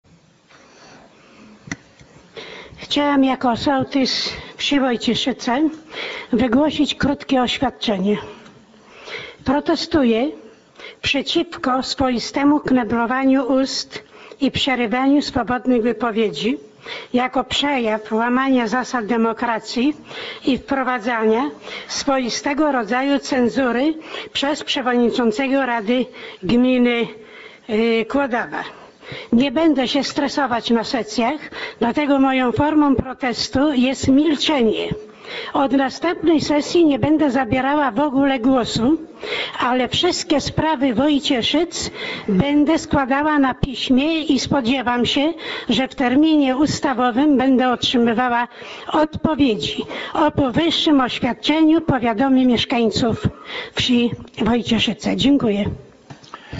Ponieważ taki incydent zdarzył się nie po raz pierwszy, sołtys Regina Korona nie mogła puścić go płazem i pod koniec sesji wygłosiła krótkie oświadczenie: